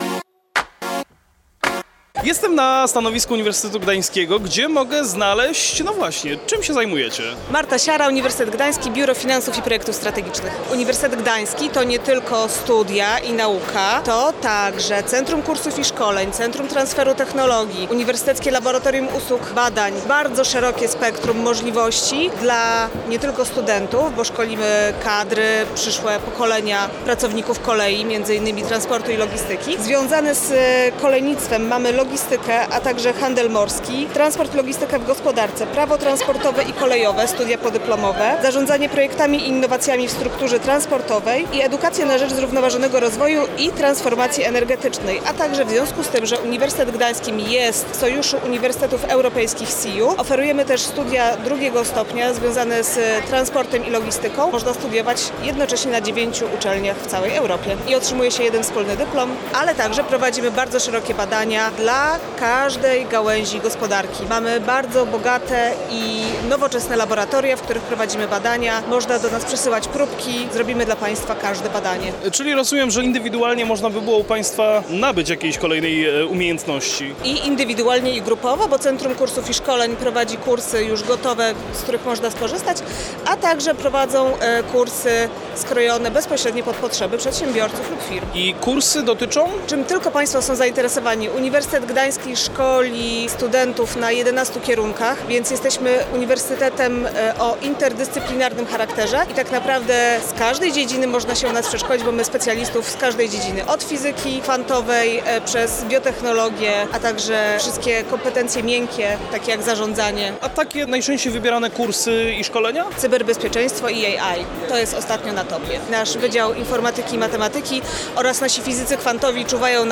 Zapraszamy Was do wysłuchania trzeciego wywiadu z serii materiałów nagranych podczas 16. Międzynarodowych Targów Kolejowych TRAKO 2025!